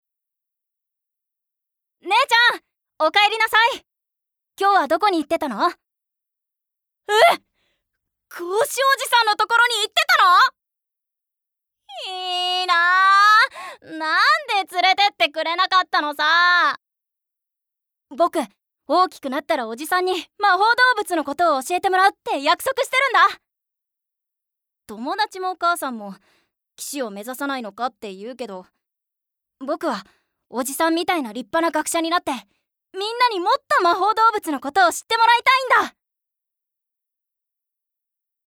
方言 兵庫弁、関西弁